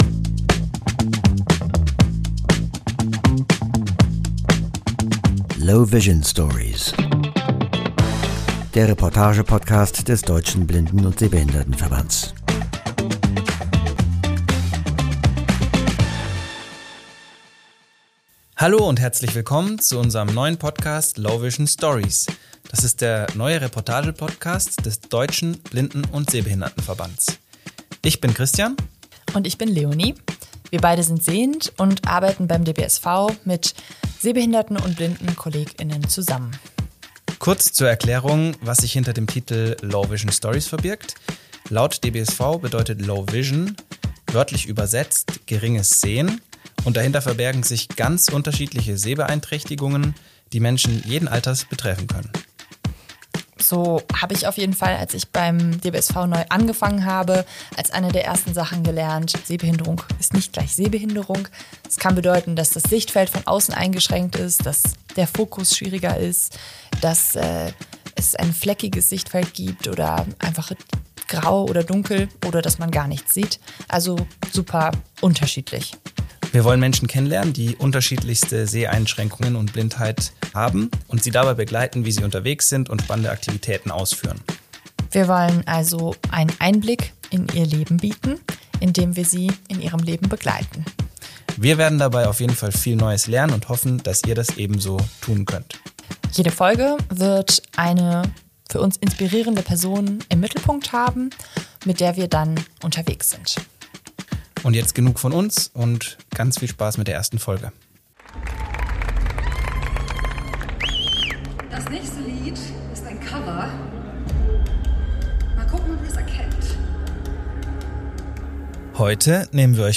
Wir haben sie im Berliner Club Huxleys getroffen und sie gefragt, wie es ist als blinde Frau vor großem Publikum zu stehen. Sie hat uns erzählt, was sie inspiriert und was sie sich von der Veranstaltungsbranche wünscht.